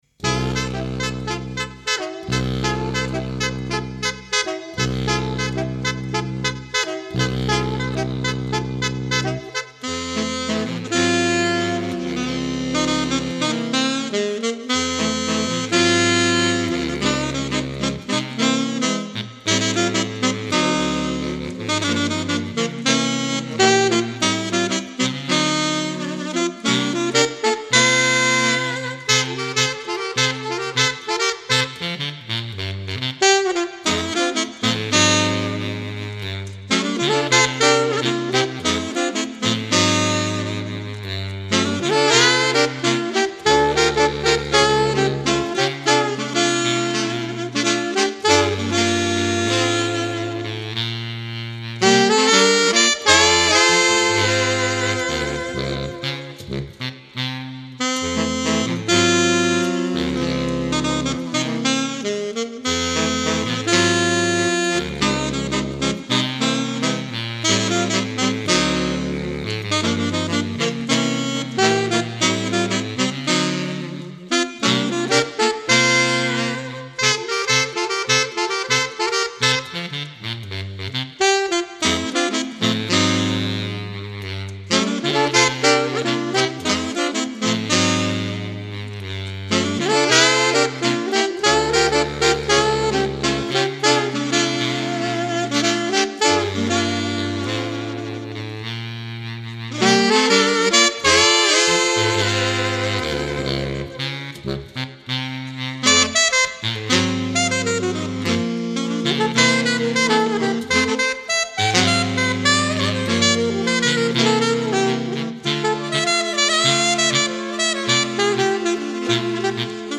(Latin Funk Music)